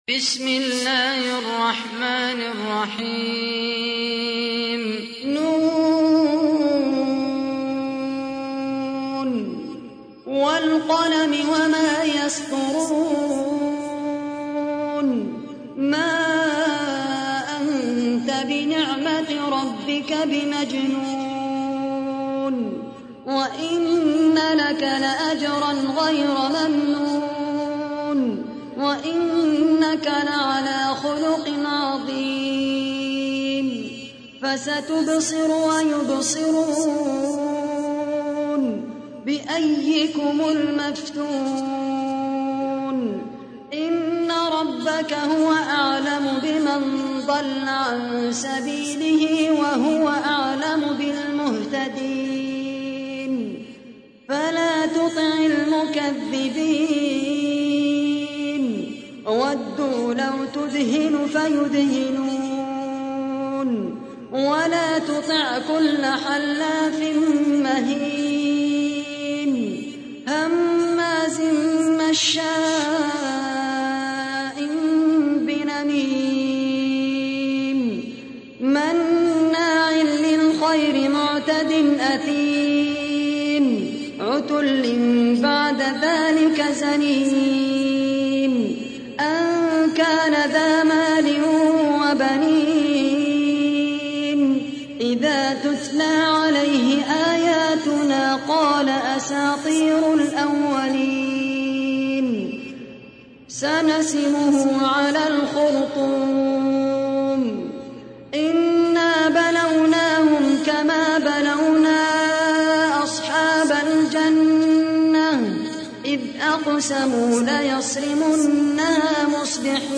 تحميل : 68. سورة القلم / القارئ خالد القحطاني / القرآن الكريم / موقع يا حسين